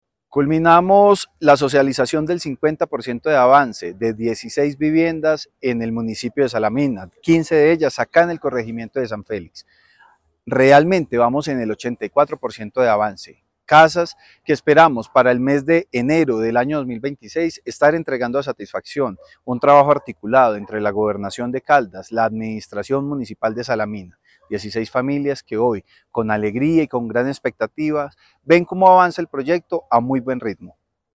Francisco Javier Vélez Quiroga, secretario de Vivienda y Territorio.